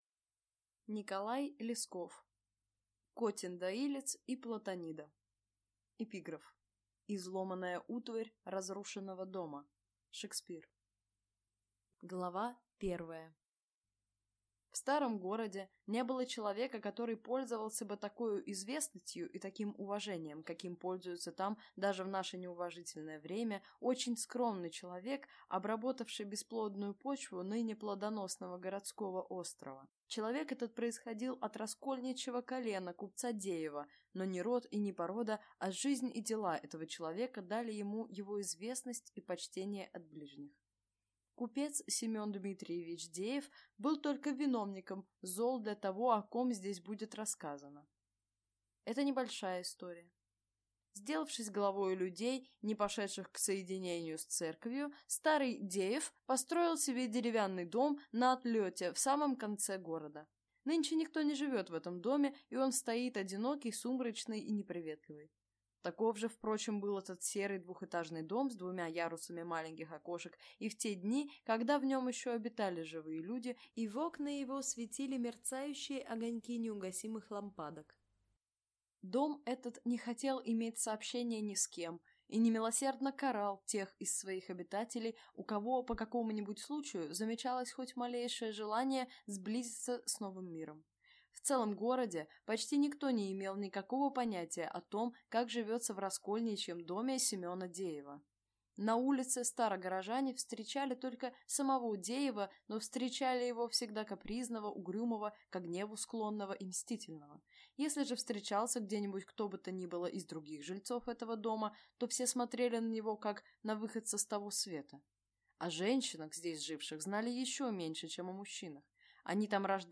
Аудиокнига Котин доилец и Платонида | Библиотека аудиокниг